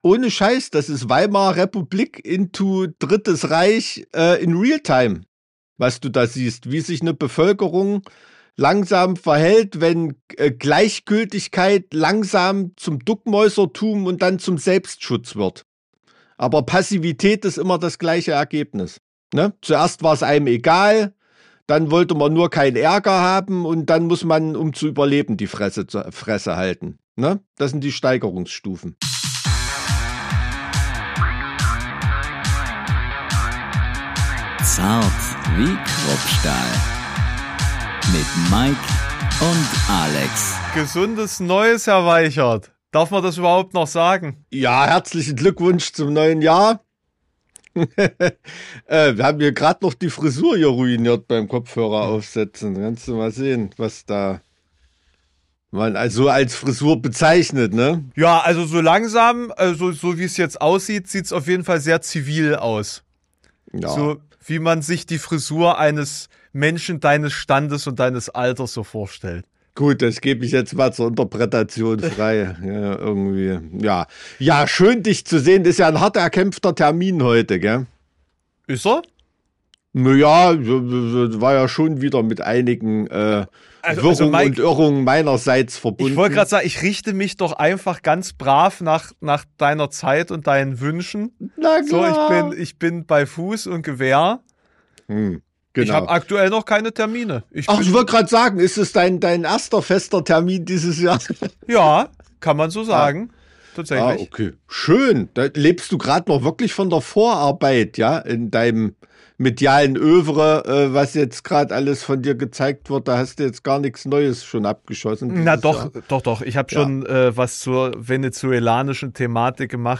Beschreibung vor 3 Monaten Willkommen in einem neuen Jahr und einer Welt in der alte Männer Politik des letzten Jahrtausends machen. Während sich Trump immer neue Länder aussucht, die er für seine Zwecke ausbeuten will, geht um schlechtes politisches Timing, eine Zeit die zu schnell läuft als dass man noch hinterherkommt und einer Gegenwart die sehr an die Vergangenheit erinnert. Kleiner Servicehinweis: Die beiden Chaoten haben es heute geschafft, irgendwas an der Soundeinstellung zu versauen.